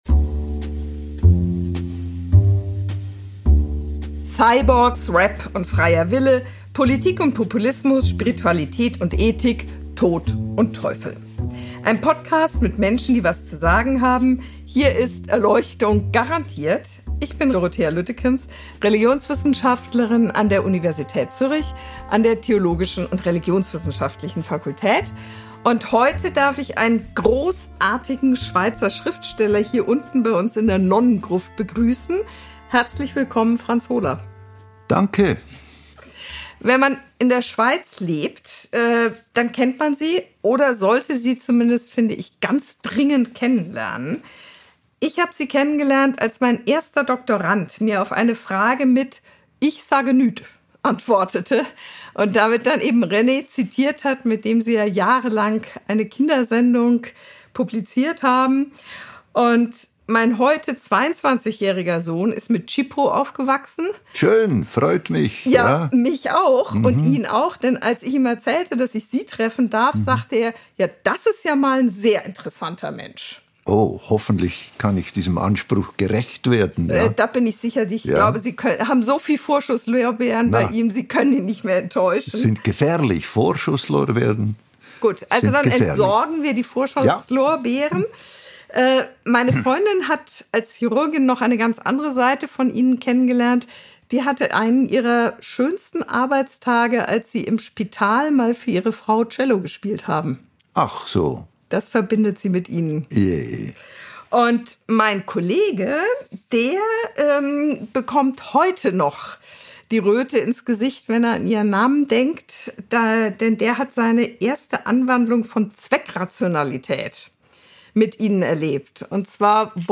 Im Podcast trägt er unveröffentlichte Gedichte vor, das «Gebet der Ungläubigen» und ein Neujahrsgedicht, und liest kurze Geschichten wie «Die Taube» und «Die Konferenz». Ausserdem erzählt er vom Cellospielen und der Kraft der Kunst, von Nonnen, die rund um die Uhr beten, und von Humor als Trauerarbeit.